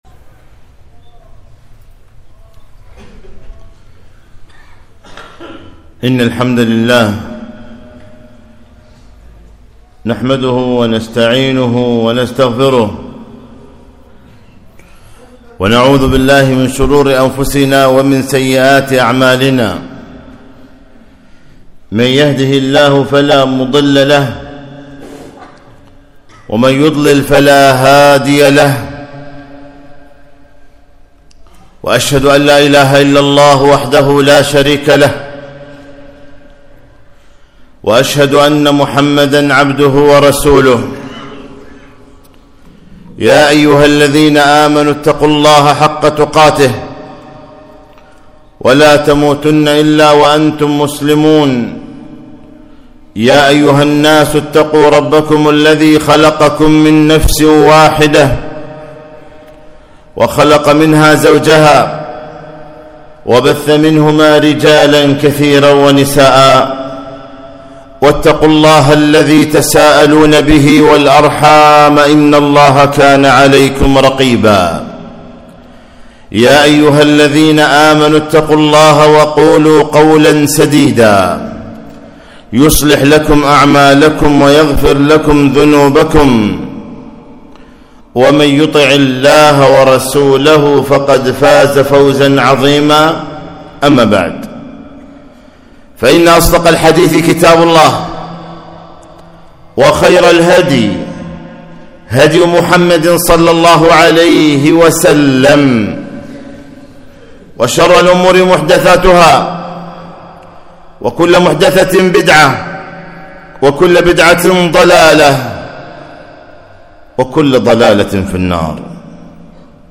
خطبة - وقفات قبل رمضان